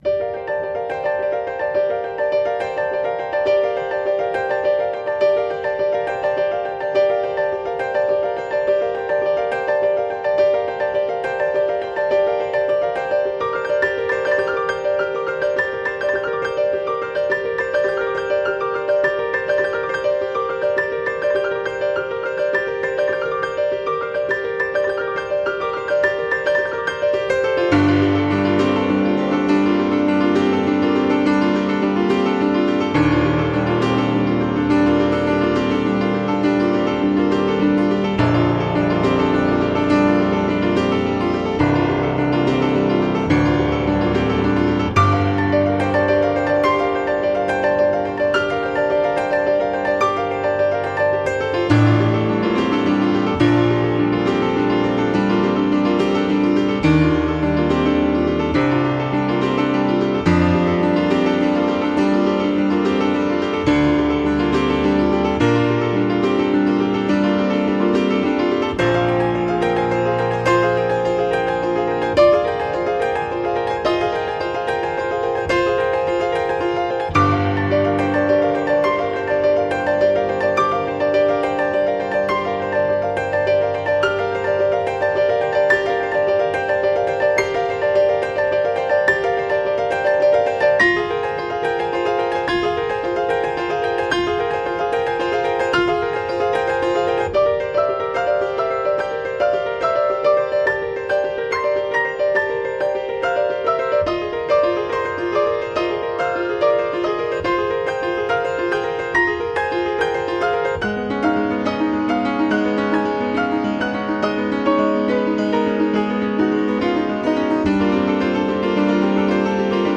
pianist in skladatelj